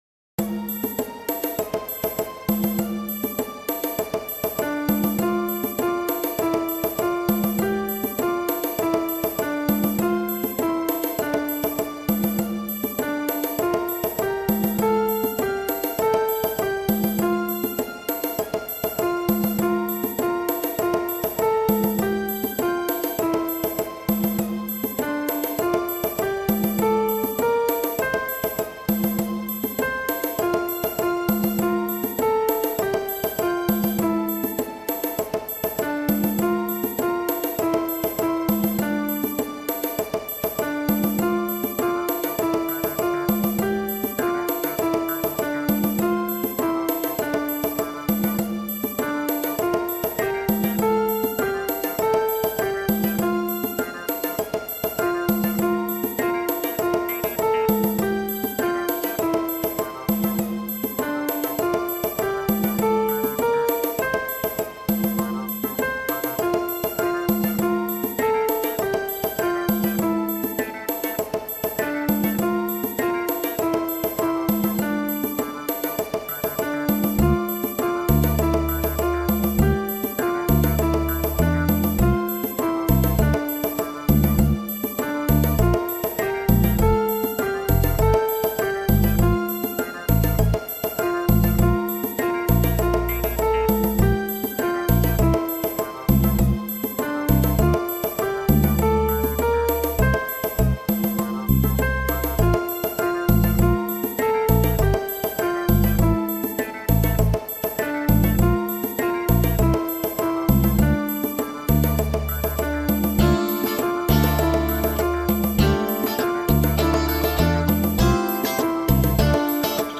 I’ve made two versions that are rhythmic because BIAB is lousy at free chant.